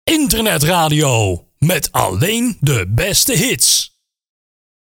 • Geen Sound Effects (SFX)
• Geluid geoptimaliseerd
Zonder Soundeffects